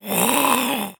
Goblin_03.wav